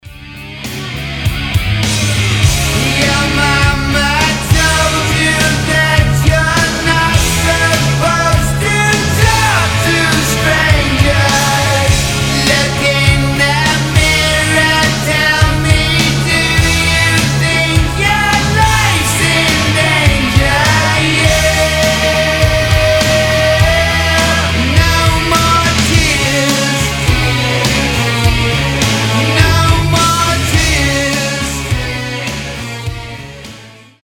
рок
heavy metal , glam metal